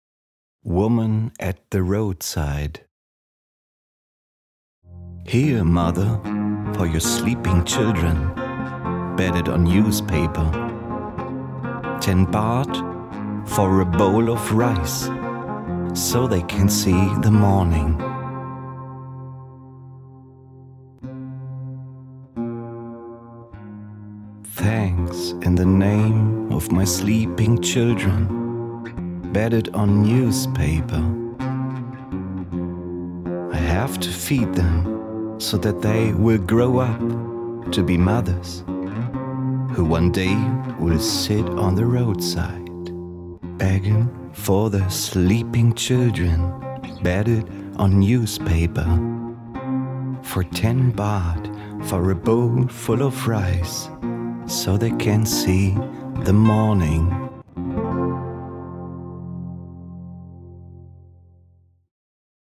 Englische Gedichte Woman At The Roadside von Tilly Boesche-Zacharow Rezitation